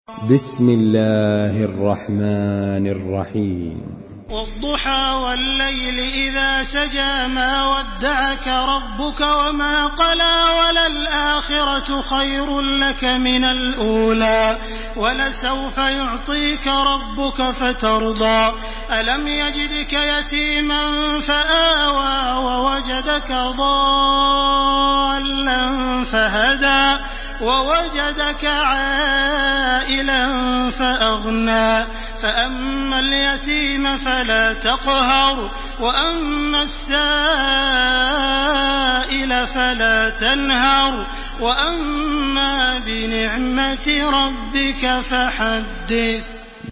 Surah Ad-Duhaa MP3 in the Voice of Makkah Taraweeh 1429 in Hafs Narration
Surah Ad-Duhaa MP3 by Makkah Taraweeh 1429 in Hafs An Asim narration.
Murattal